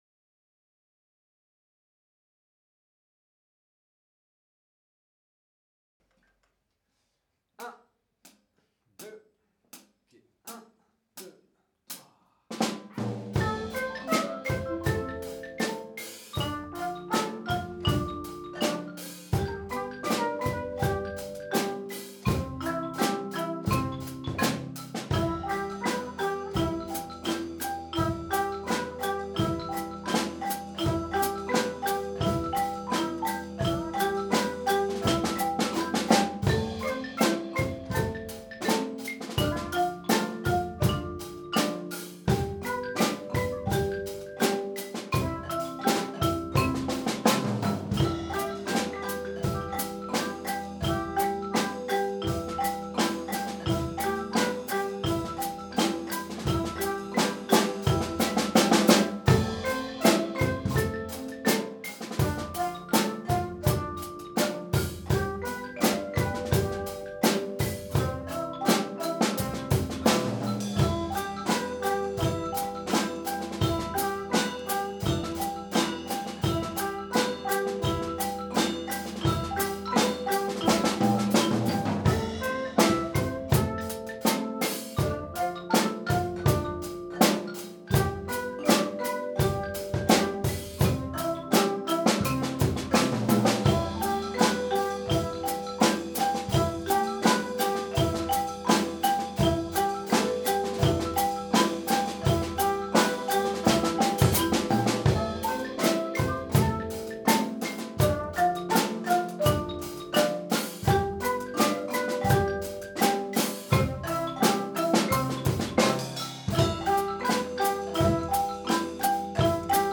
🎼 Grille d'accords que vous avez choisi sur votre compo
L'accompagnement audio de votre compo